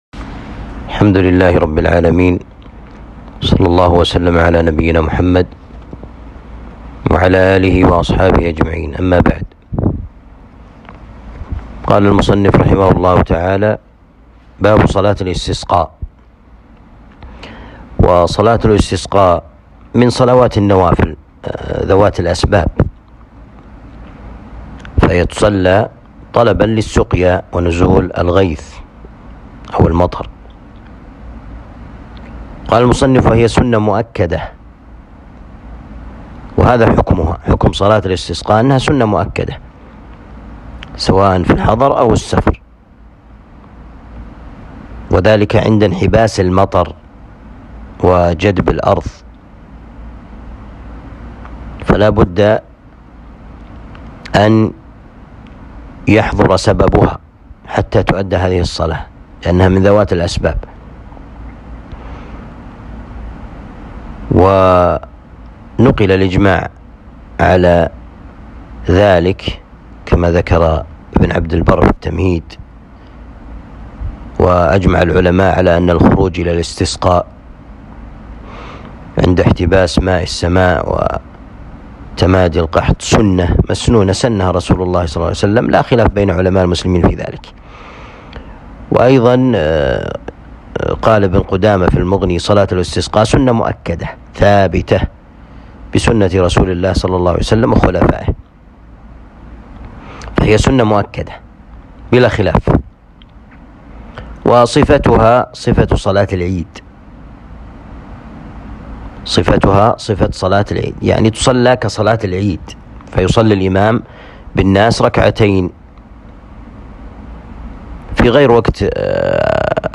الدروس شرح كتاب آداب المشي إلى الصلاة